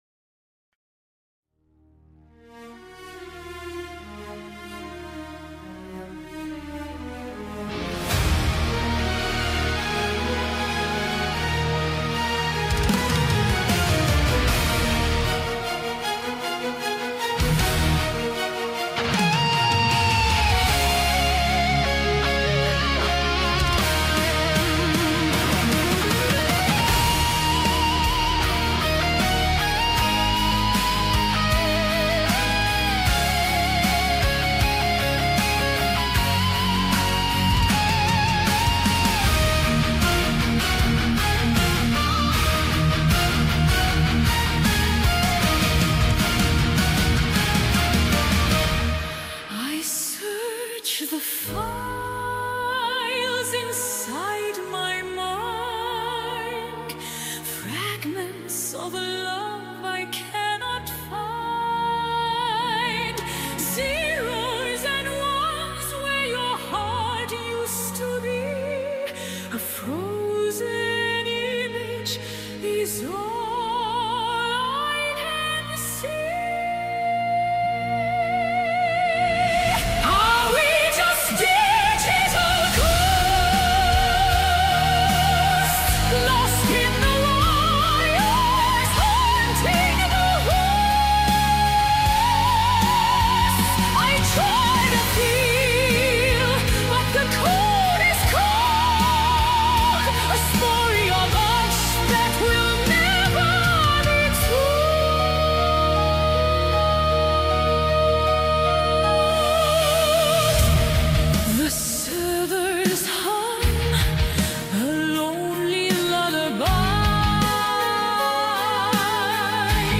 Digital Ghosts -The AI Requiem {Symphonic Metal Ballad} AI